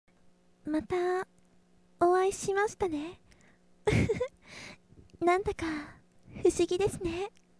ネット声優に50のお題はこちらお借りしました